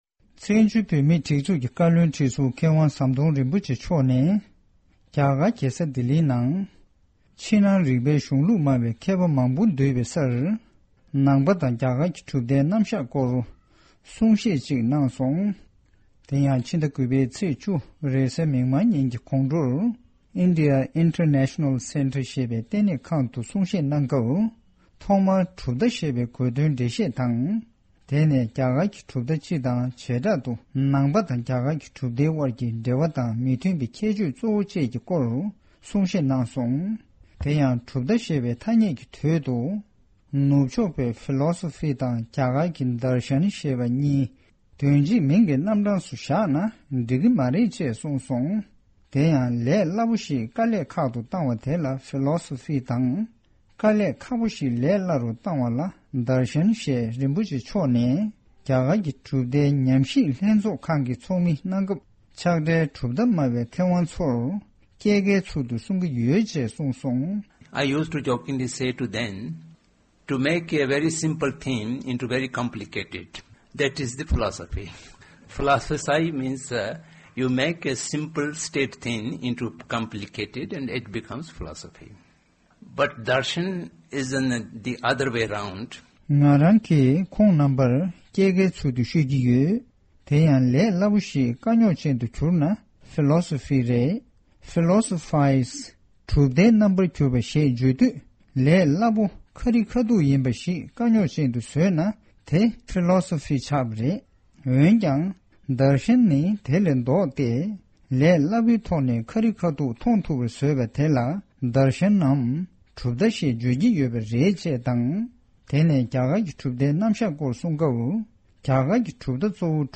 བཀའ་བློན་ཁྲི་ཟུར་ཟམ་གདོང་རིན་པོ་ཆེས་ནང་པ་དང་རྒྱ་གར་གྱི་གྲུབ་མཐའི་སྐོར་གསུང་བཤད་གནང་བ།
བཀའ་བློན་ཁྲི་ཟུར་མཁས་དབང་ཟམ་གདོང་རིན་པོ་ཆེ་མཆོག་གིས་ཕྱི་ཟླ་དགུ་པའི་ཚེས་བཅུའི་ཕྱི་དྲོ་རྒྱ་གར་གྱི་རྒྱལ་ས་ལྡི་ལིའི་ནང་དུ་ནང་པ་དང་རྒྱ་གར་གྱི་གྲུབ་མཐའི་རྣམ་གཞག་སྐོར་གསུང་བཤད་ཞིག་གནང་ཡོད་པ་